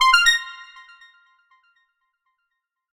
retweet_send.ogg